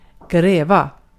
Ääntäminen
IPA : /dɪɡ/